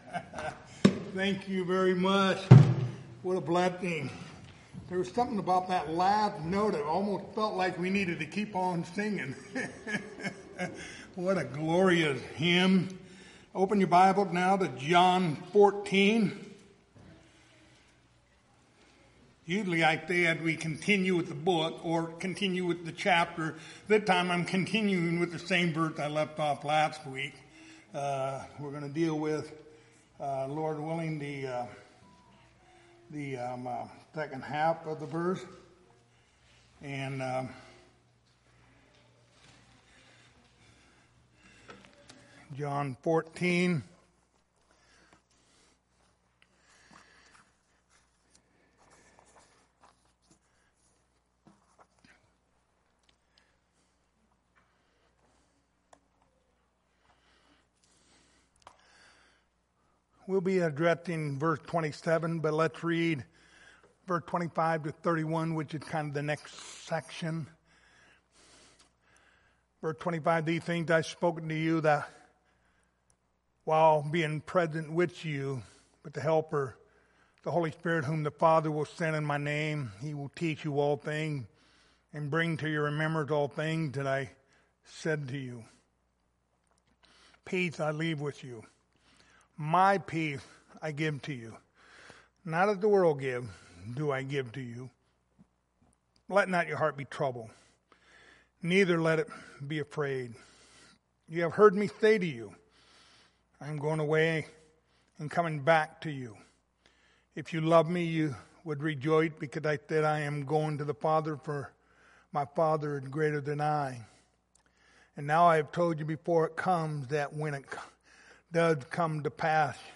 Passage: John 14:27 Service Type: Wednesday Evening Topics